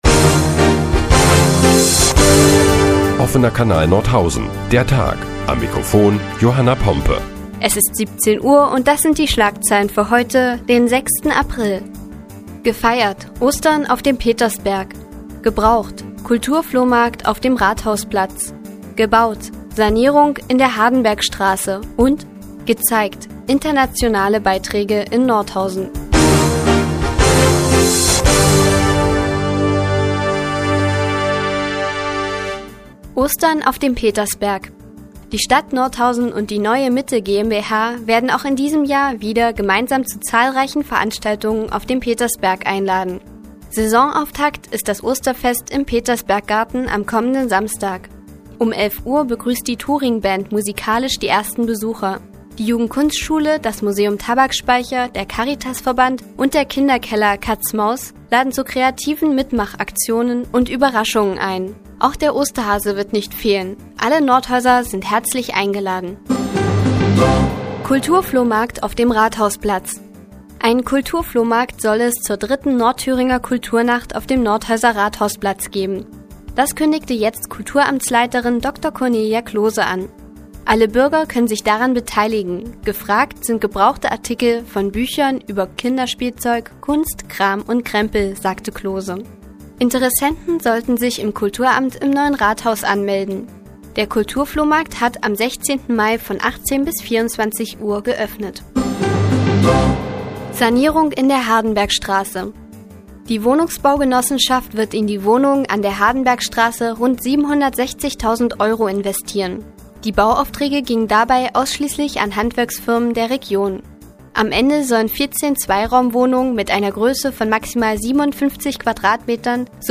Die tägliche Nachrichtensendung des OKN ist nun auch in der nnz zu hören. Heute geht es um ein Osterfest auf dem Petersberg und um einen Kulturflohmarkt auf dem Rathausplatz.